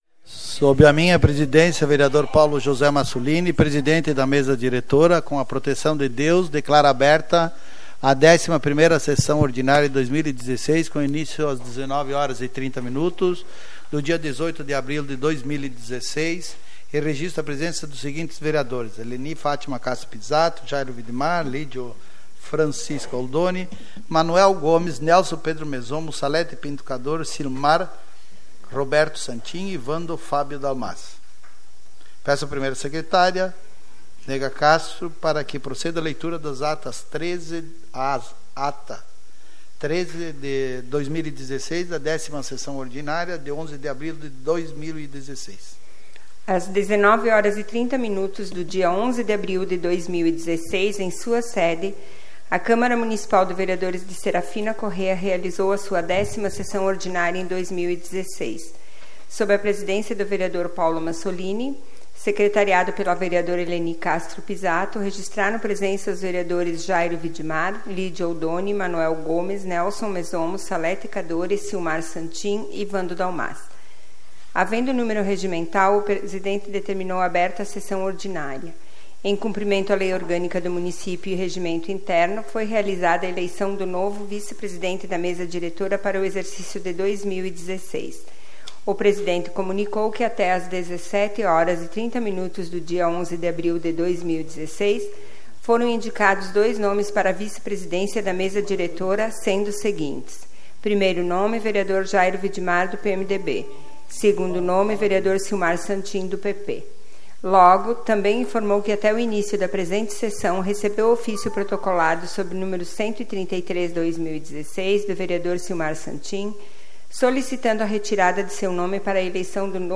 SAPL - Câmara de Vereadores de Serafina Corrêa - RS
Tipo de Sessão: Ordinária